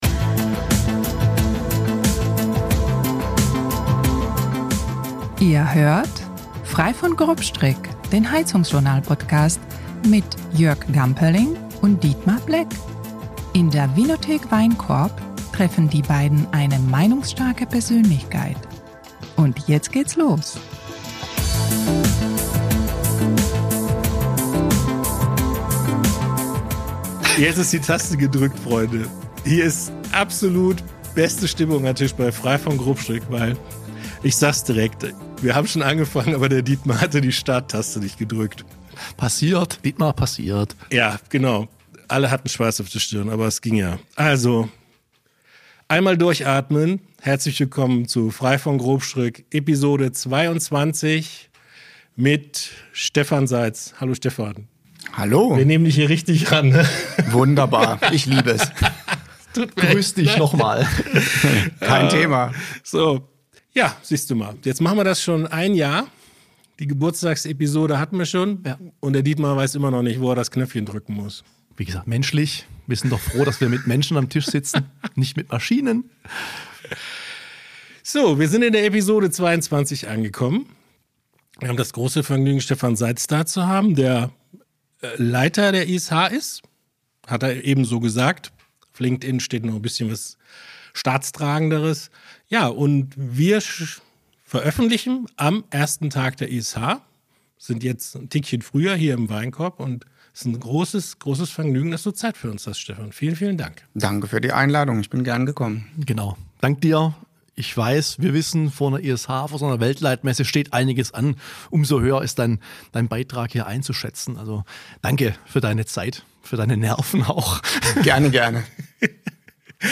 Kurz herrscht genüssliche Stille! Locker bis launig, meinungsstark und informativ, das ist Frei von Grobstrick, der HeizungsJournal-Podcast.